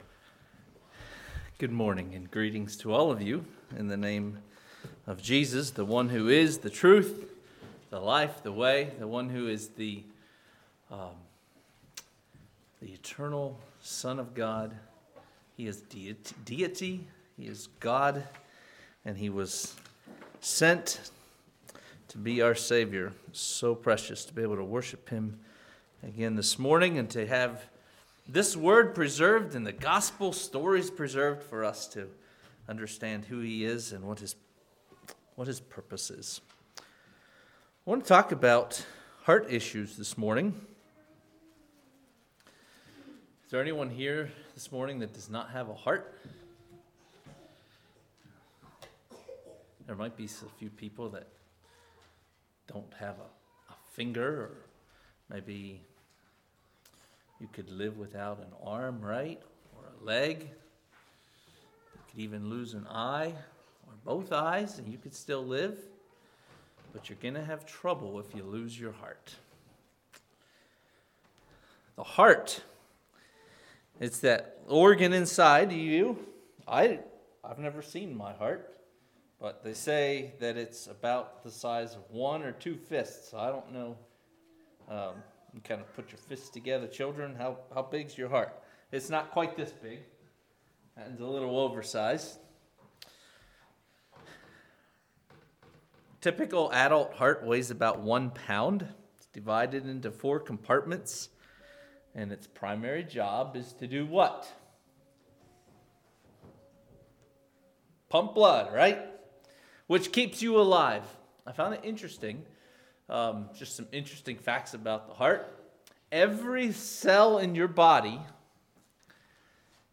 Play Now Download to Device Heart Issues Congregation: Lake View Speaker